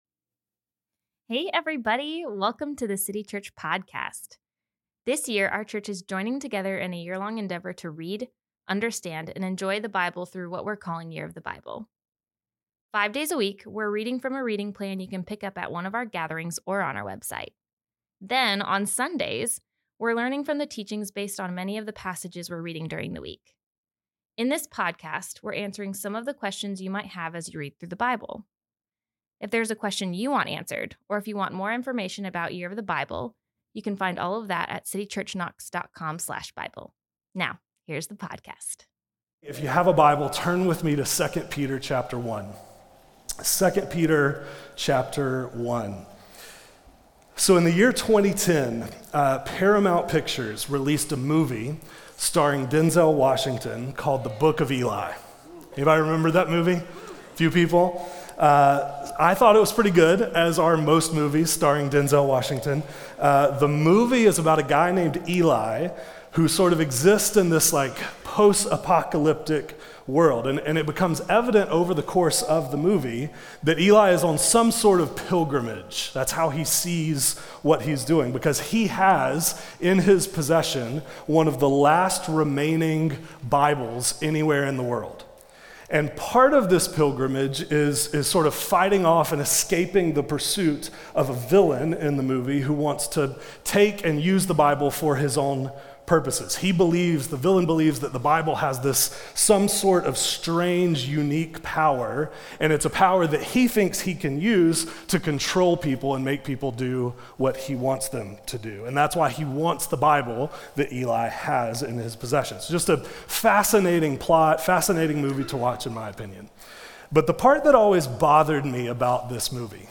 Teaching from City Church in Knoxville, TN. We desire to be a Jesus-centered family on mission.